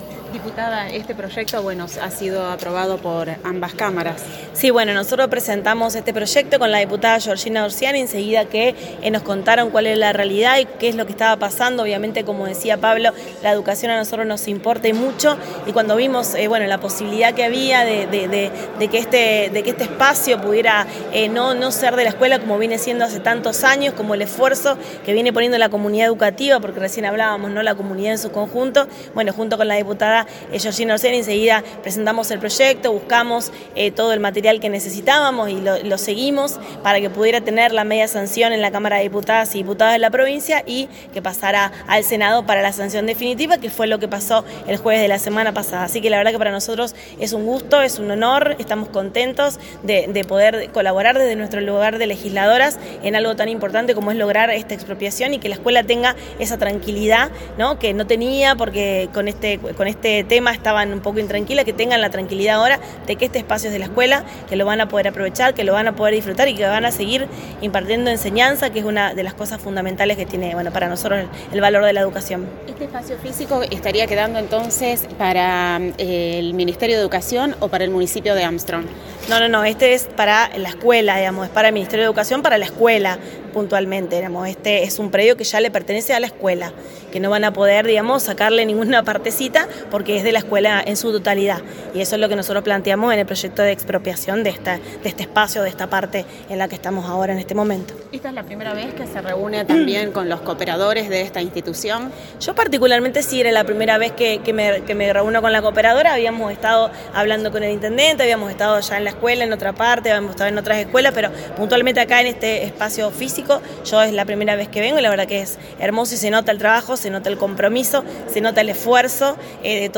Convocada la prensa a la Escuela Campo Spagnolo tuvimos la posibilidad de hablar con autoridades Provinciales, Locales. la noticia es que la Escuela Campo Spagnolo podrá disponer del inmueble recuperado y las instalaciones en donde se encuentra.
Dip. Silvana Di Stefano